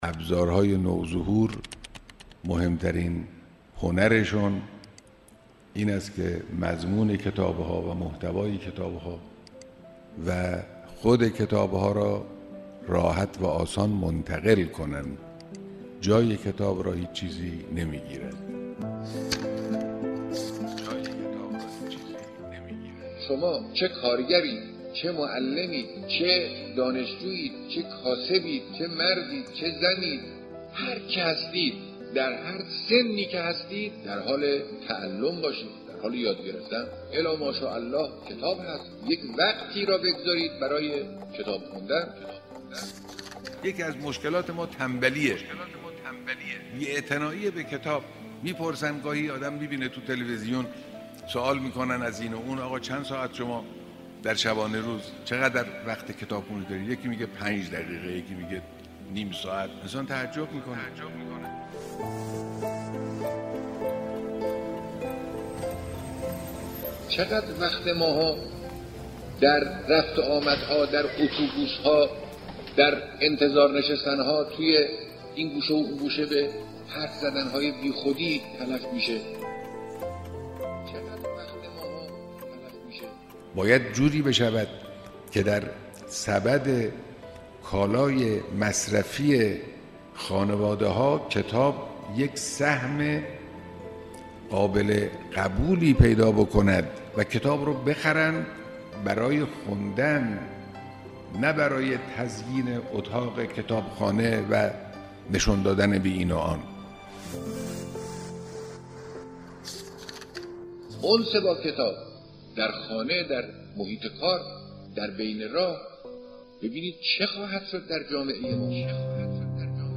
کتاب صوتی پیغام ماهی ها | فرهنگیاران